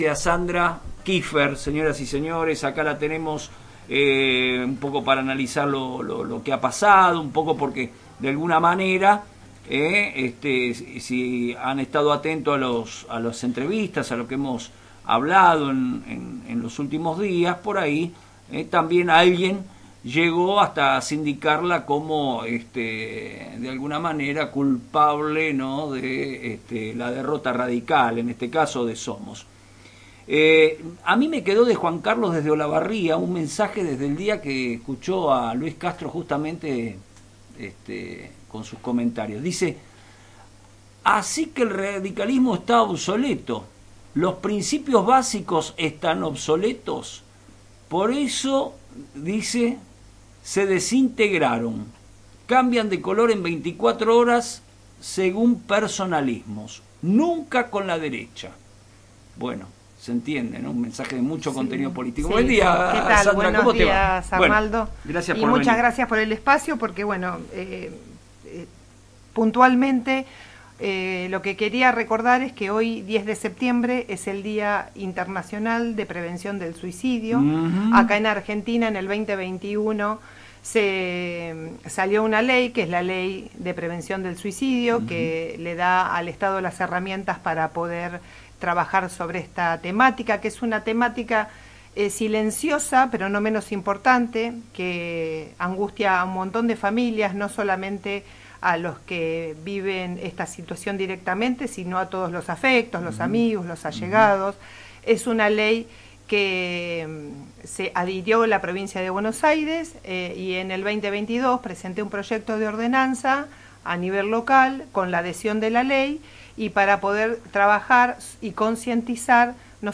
La actual concejala Sandra Kifer, por el bloque «Es con vos», participo de una entrevista en FM Reflejos donde analizó el resultado de las elecciones y trazó lineamientos de cara al futuro para la UCR; partido al que asegura sigue perteneciendo.